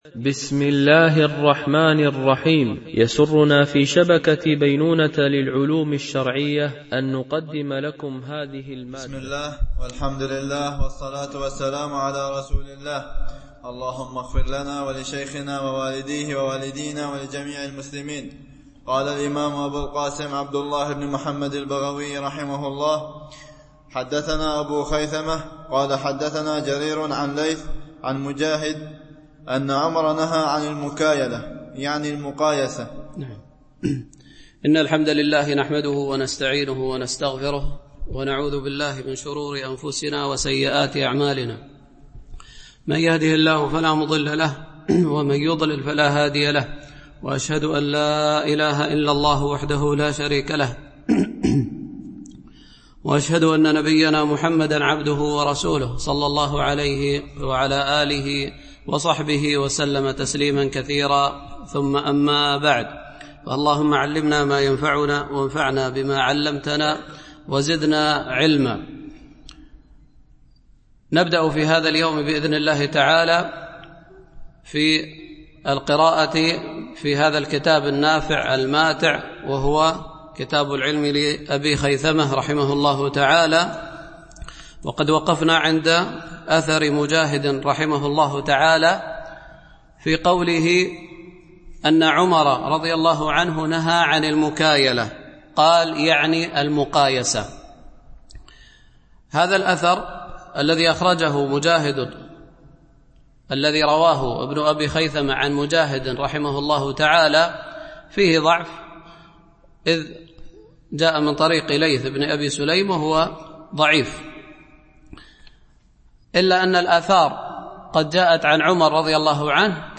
شرح كتاب العلم لأبي خيثمة ـ الدرس 22 (الأثر 65-67)